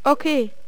horseman_ack2.wav